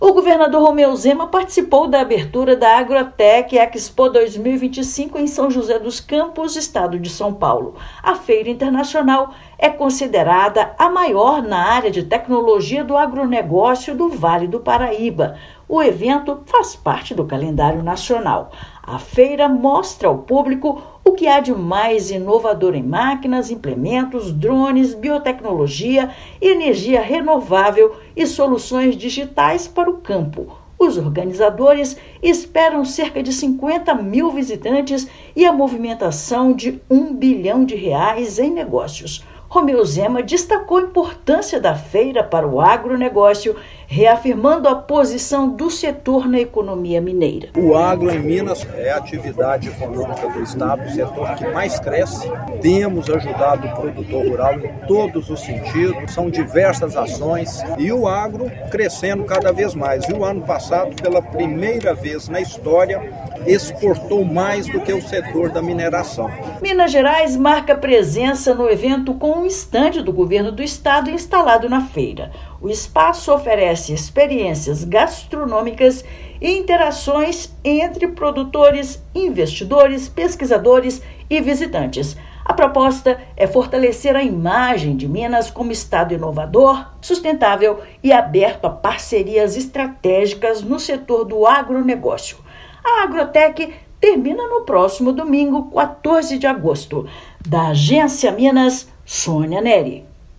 [RÁDIO] Governador participa da Agrotech Expo e apresenta revolução do setor em Minas Gerais
Em uma das feiras mais importantes para o setor, chefe do Executivo mineiro falou sobre o potencial do agronegócio no estado. Ouça matéria de rádio.